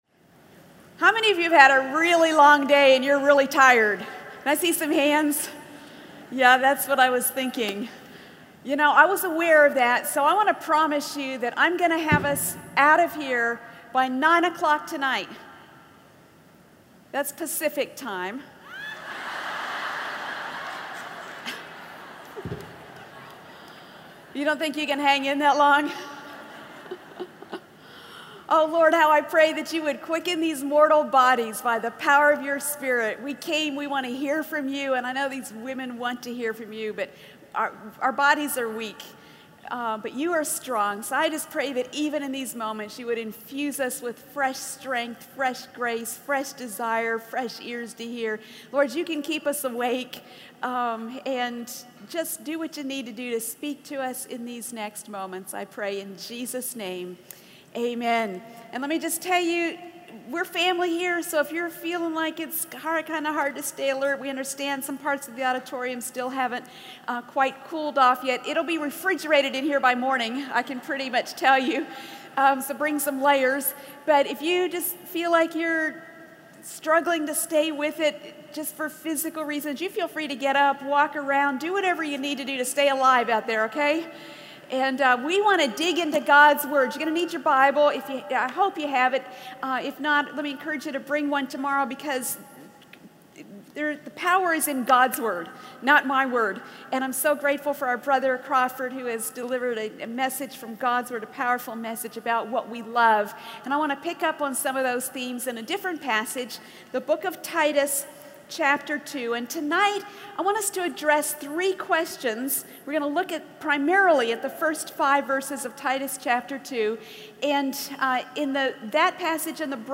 | True Woman '10 Indianapolis | Events | Revive Our Hearts